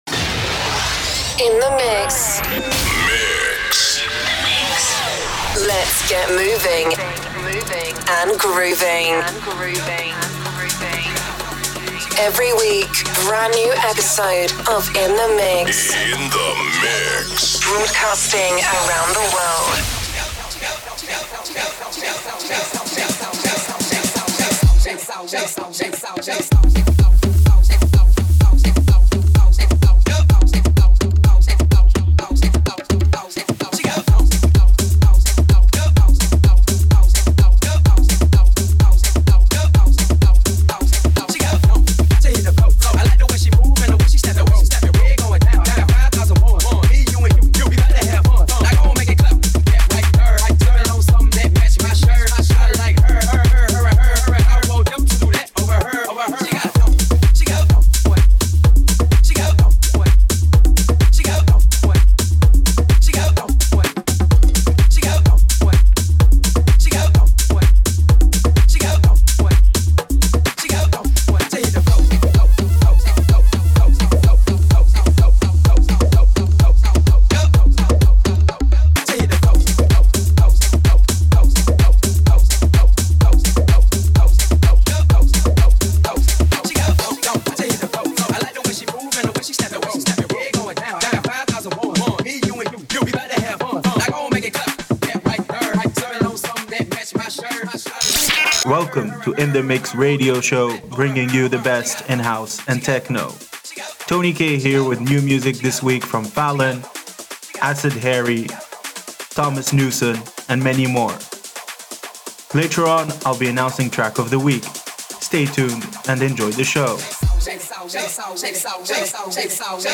the very best rhythmic and grooving electronic music
Expect 3 deck live mixing and an organic flow